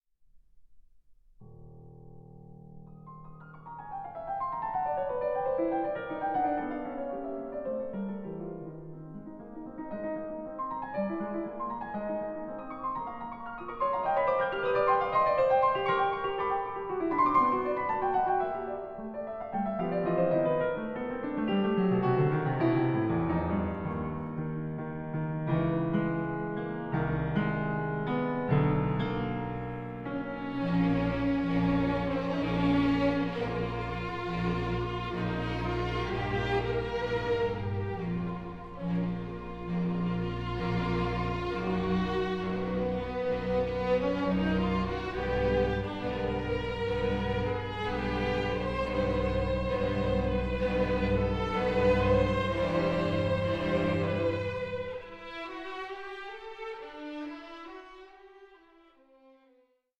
Lento 7:46
and Strings bursts with irony and theatrical flair